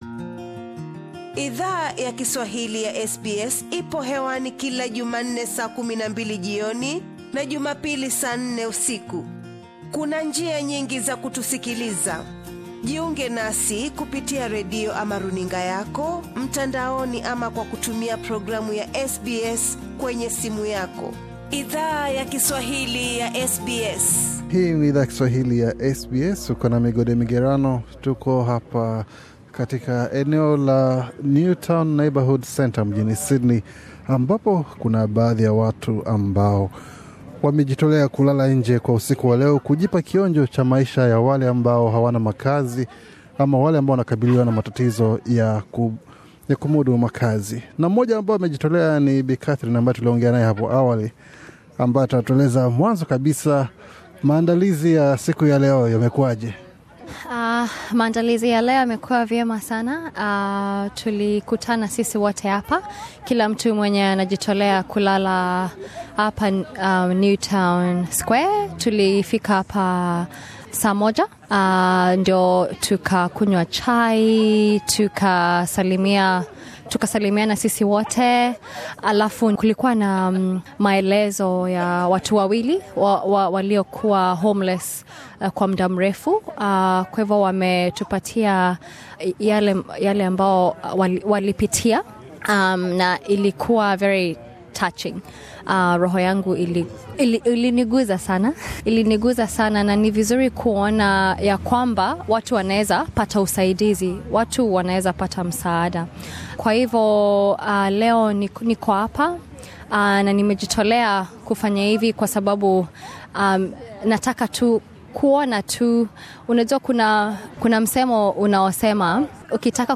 Ilikuwa usiku wenye baridi sana tarehe 29 Agosti 2017, SBS Swahili ilipowasili katika eneo la Newtown Square katika kitongoji cha Newtown NSW.